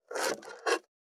477台所,野菜切る,咀嚼音,ナイフ,調理音,まな板の上,
効果音厨房/台所/レストラン/kitchen食器食材